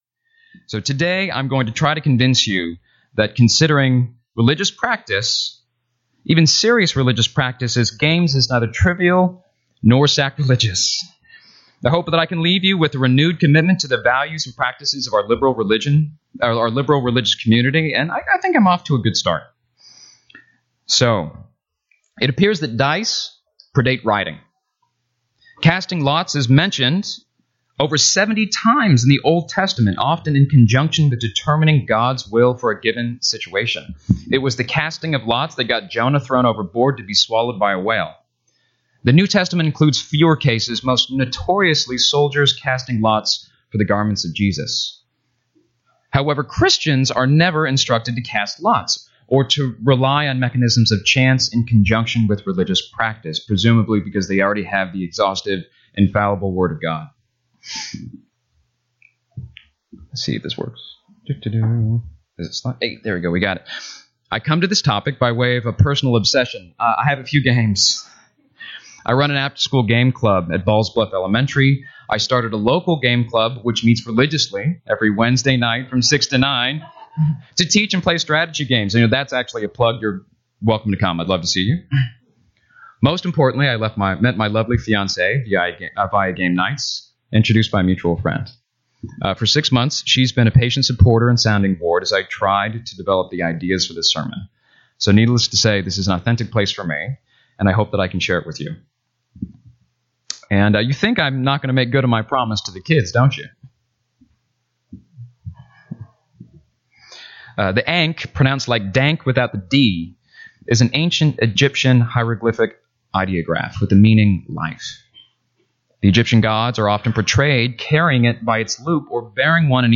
This sermon will ask listeners to reflect on worship from the perspective of play.